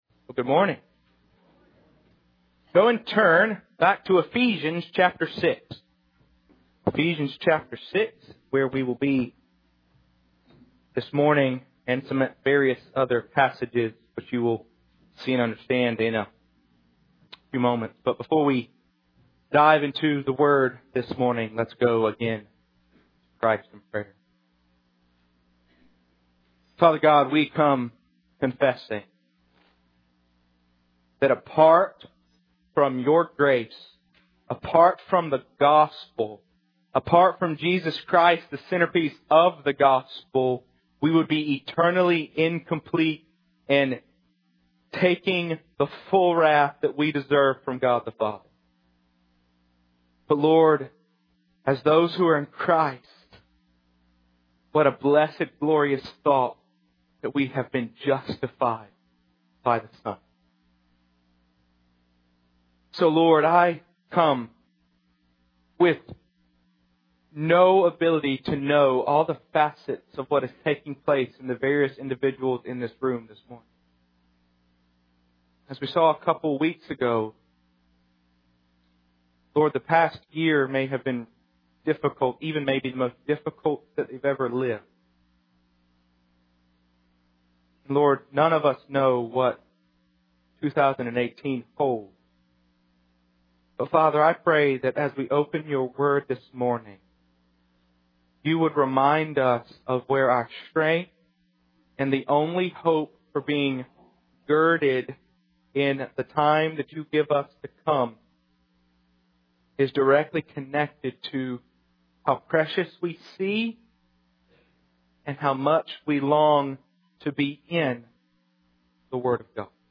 Resources on “The Seven Arrows” mentioned in the sermon: